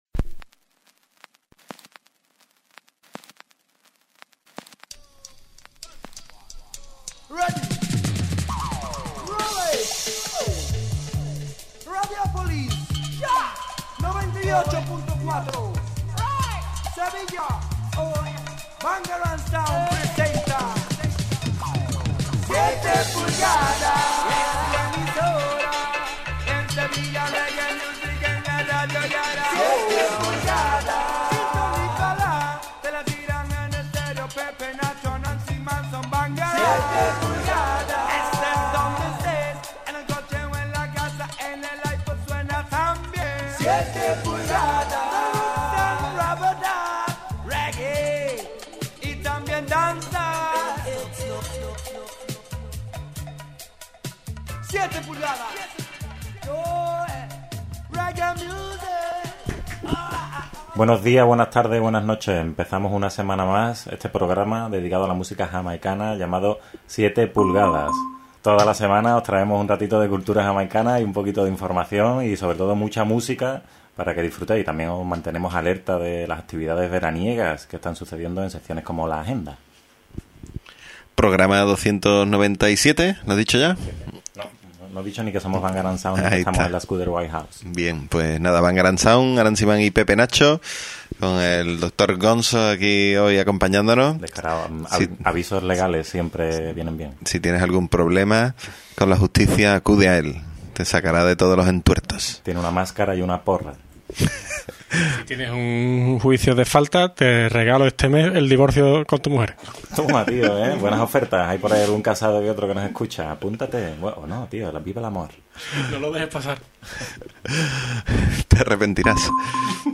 Presentado y dirigido por la Bangarang Sound y grabado en la Skuderbwoy House.